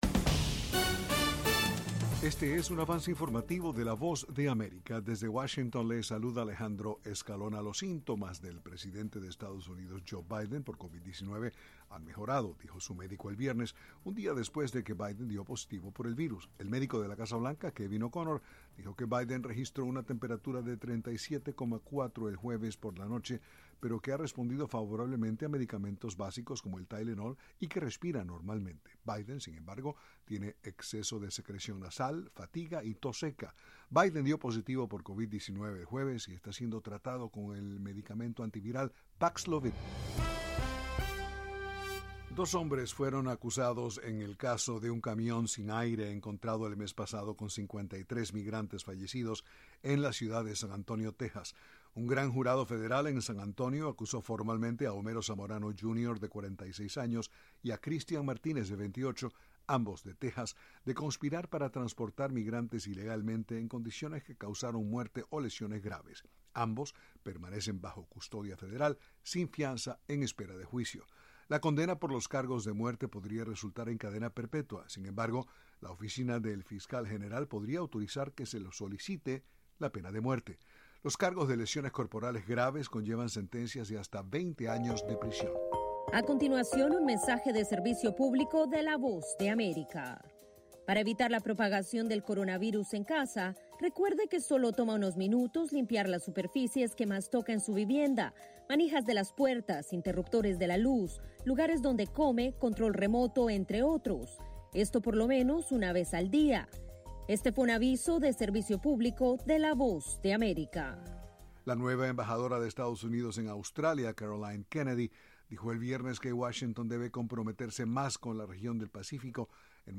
Este es un un avance informativo presentado por la Voz de América en Washington.